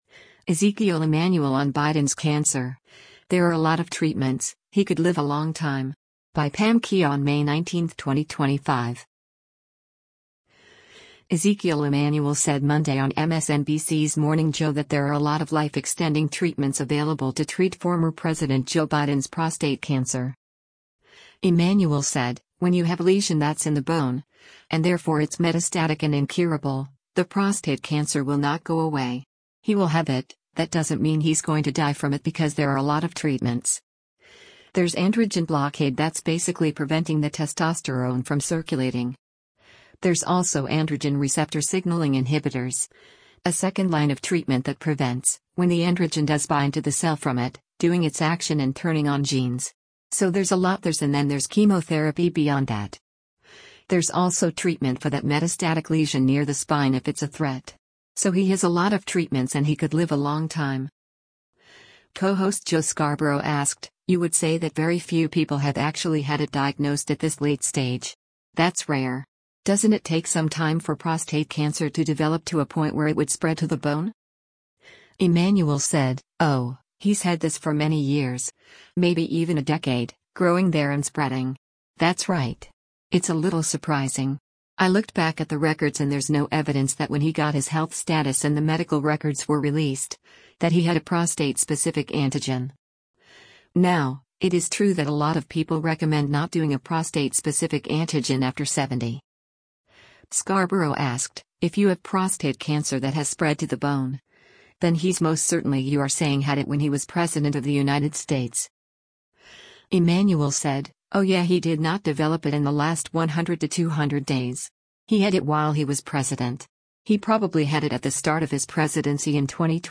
Ezekiel Emanuel said Monday on MSNBC’s “Morning Joe” that there are a lot of life-extending treatments available to treat former President Joe Biden’s prostate cancer.